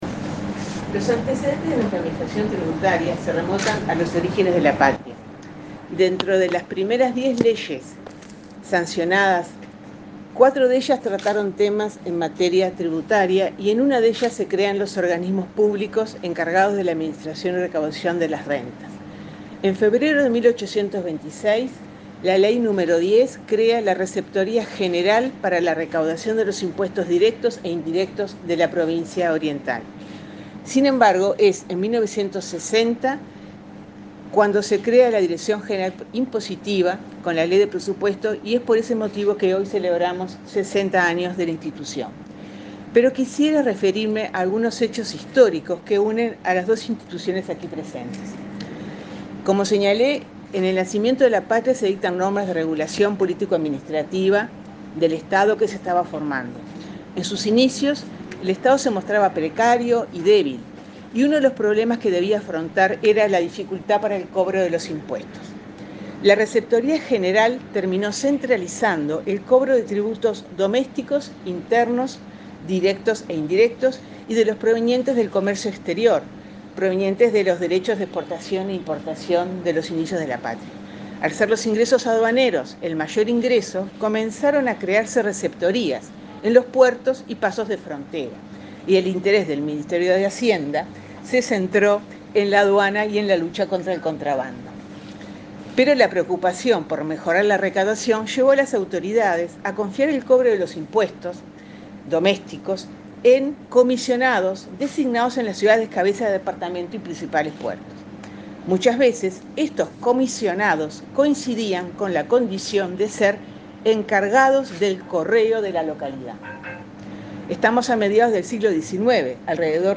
La directora general de Rentas, Margarita Faral, destacó los vínculos entre la DGI y el Correo Uruguayo como entidad colaboradora en la recaudación de impuestos. La jerarca realizó una reseña histórica de la administración tributaria, con motivo de la presentación de un sello que conmemora el 60.° aniversario del organismo, creado en 1960.